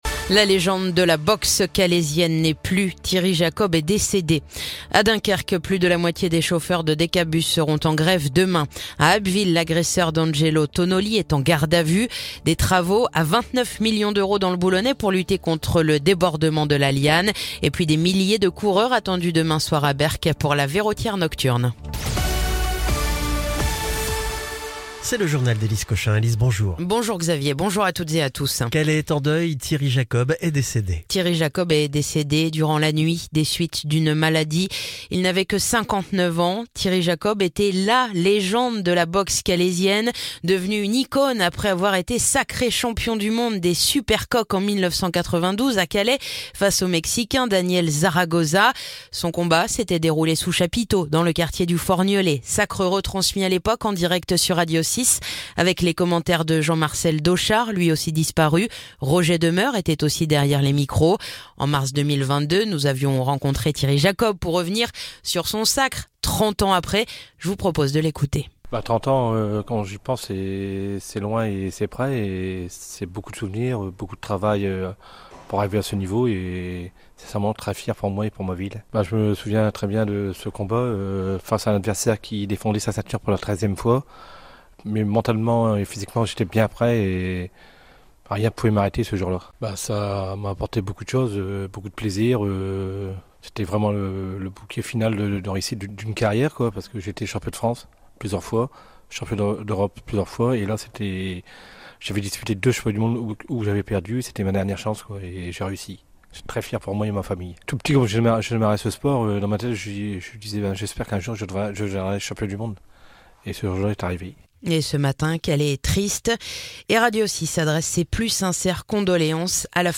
Le journal du vendredi 20 décembre